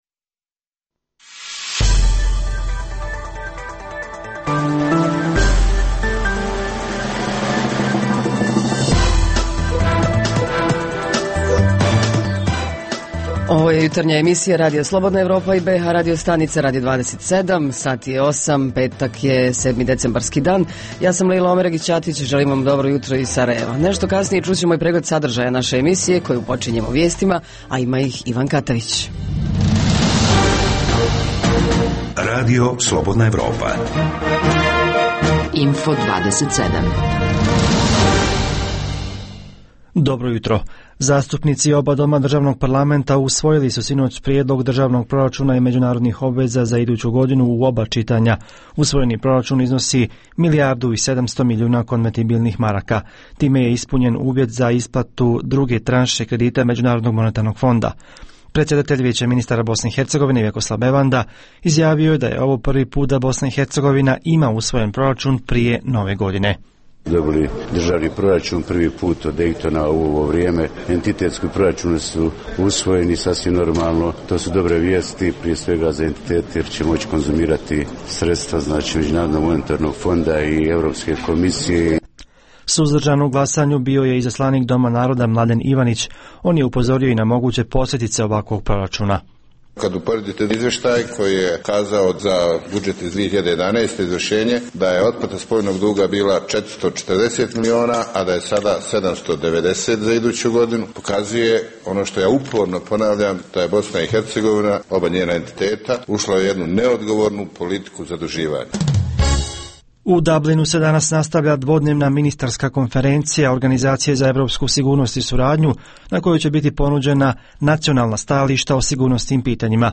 Ovog jutra javljaju se dopisnici iz Banja Luke, Brčkog, Višegada, Foče i Mostara.
Redovni sadržaji jutarnjeg programa za BiH su i vijesti i muzika.